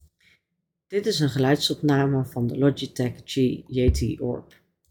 Bij alle drie de geluidsopnames zit ik ongeveer op 40 cm afstand van de microfoon en ik vond dat de microfoon mijn zachte stem goed en duidelijk oppakt.
Bij de opnamen met de Bass boost hoor je inderdaad dat de lage tonen meer naar voren worden gehaald.
Logitech-G-Yeti-Orb-Blue-VOCE-Desk-Bass-boost.mp3